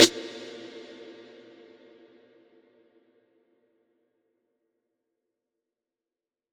SNARE - CAVEMEN.wav